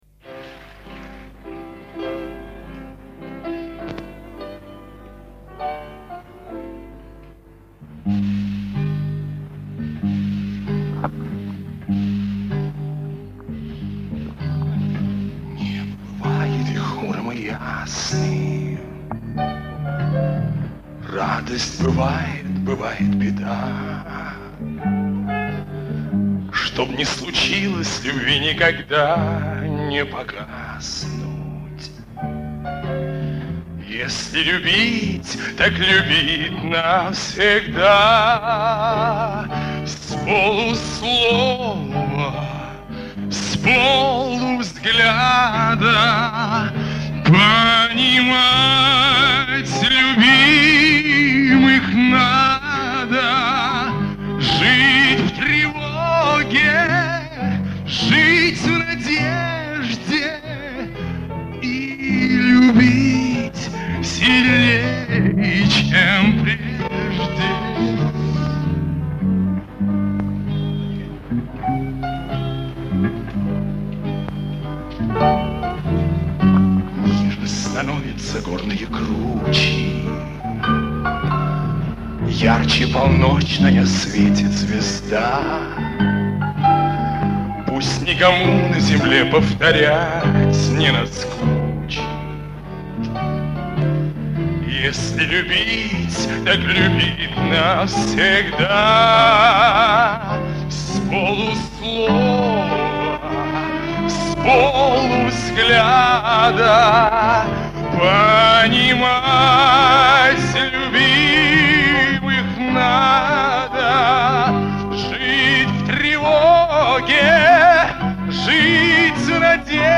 Позже на концерте в Лиепае он исполнял эту песню сольно.
сольное исполнение
с фестиваля в Лиепае